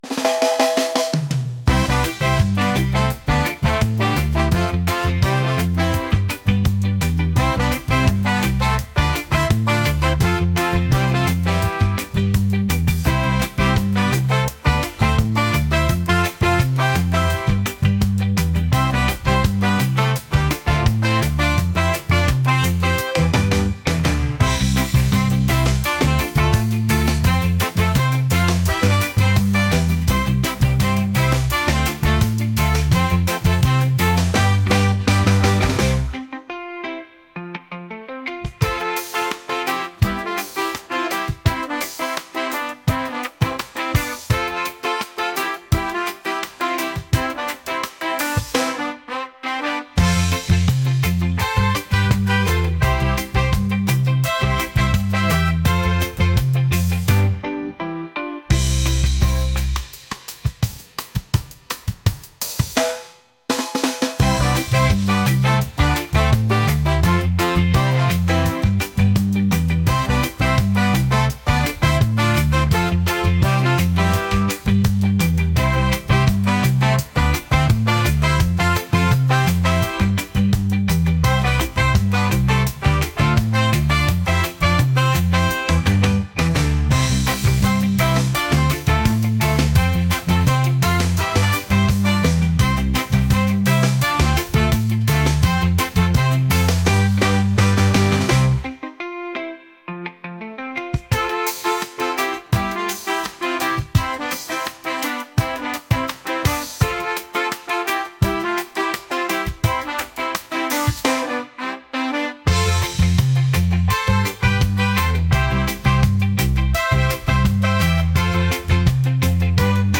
reggae | upbeat | ska